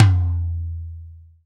TOM TOM103.wav